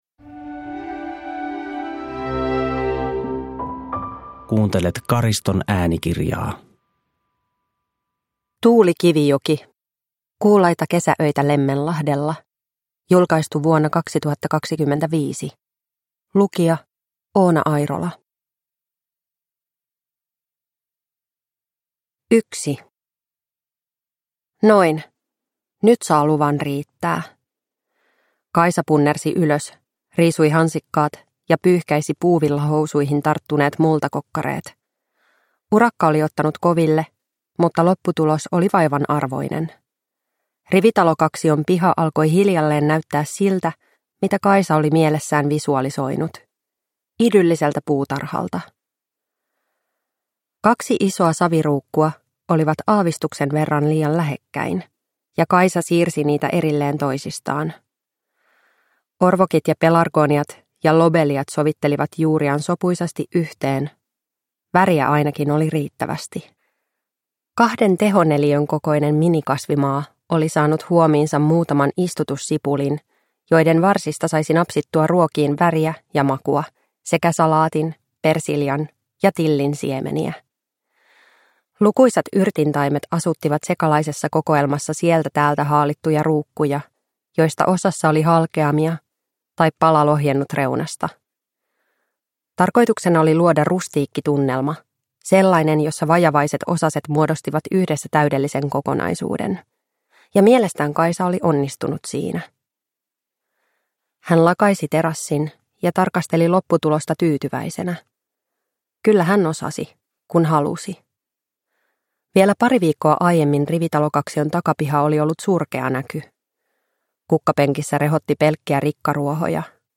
Kuulaita kesäöitä Lemmenlahdella (ljudbok) av Tuuli Kivijoki